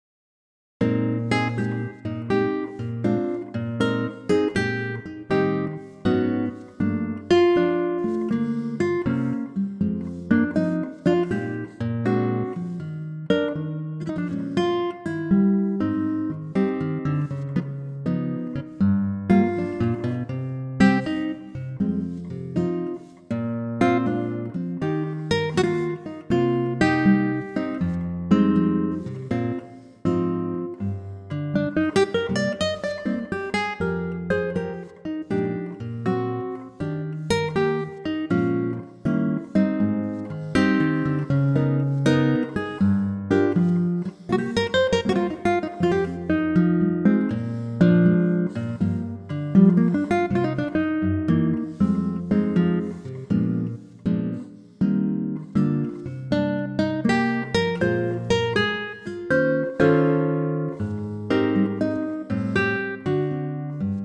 Unique Backing Tracks
key - Eb - vocal range - Bb to D
Gorgeous acoustic guitar arrangement